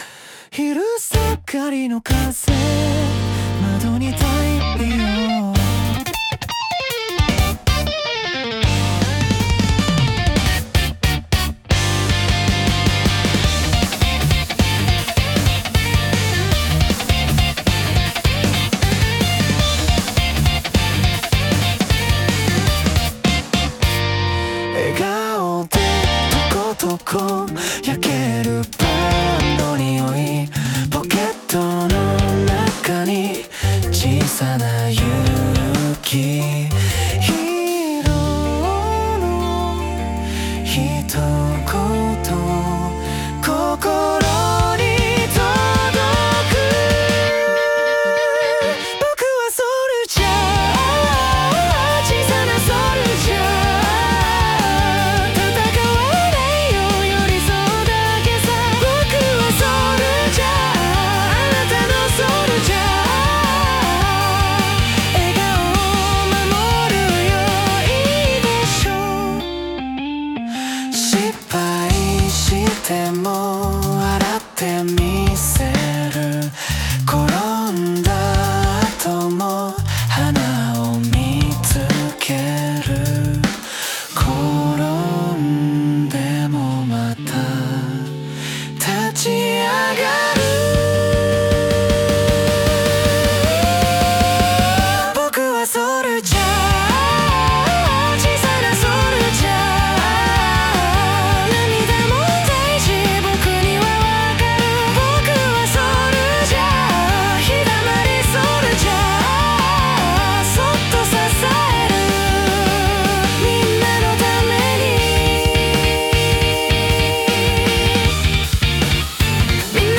男性ボーカル
イメージ：J-POP,男性ボーカル,かっこかわいい